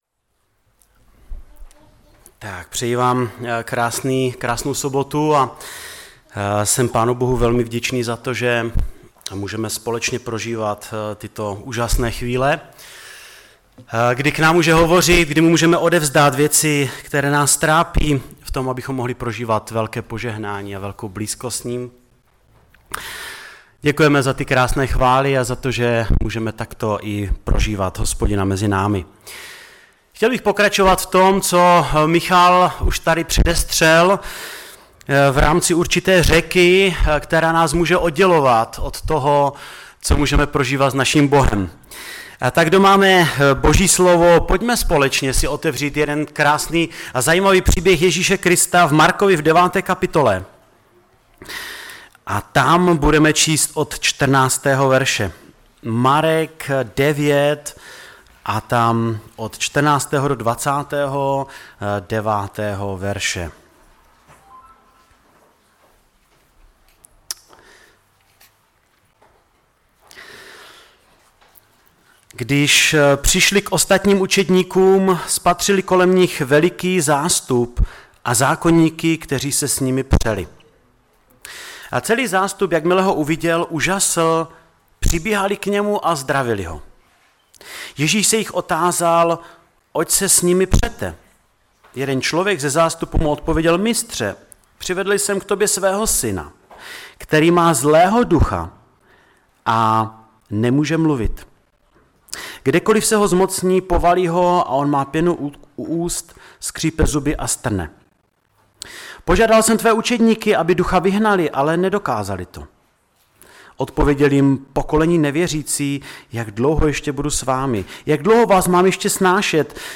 Kazatel